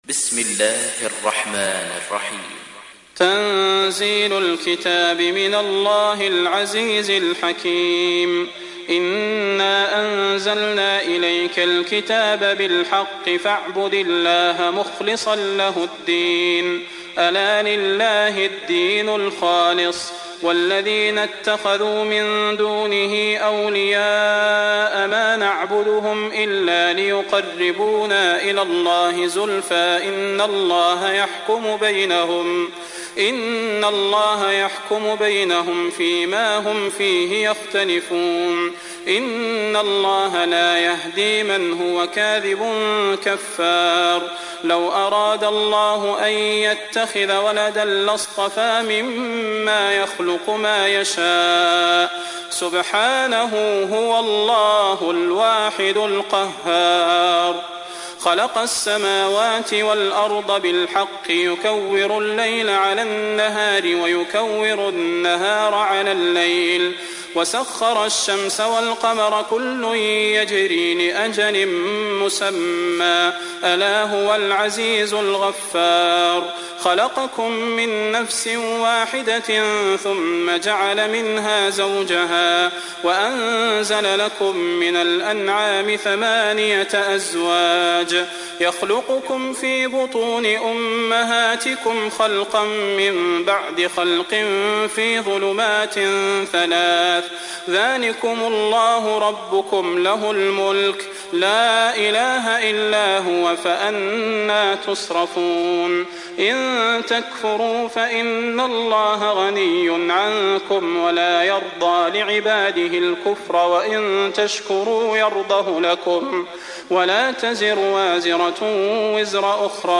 دانلود سوره الزمر mp3 صلاح البدير روایت حفص از عاصم, قرآن را دانلود کنید و گوش کن mp3 ، لینک مستقیم کامل